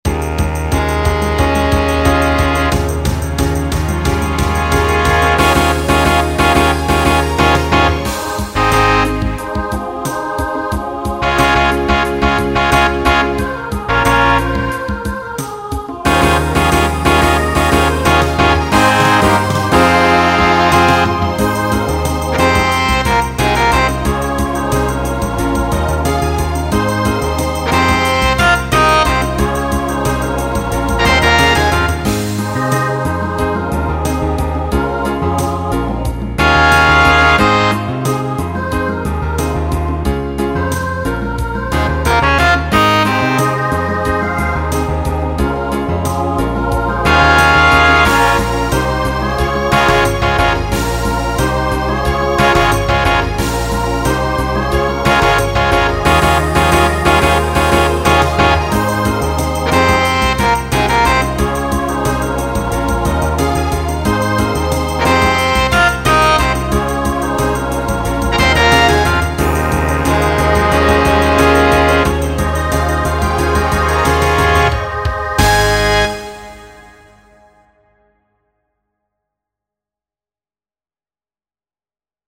Genre Pop/Dance Instrumental combo
Solo Feature , Transition Voicing SSA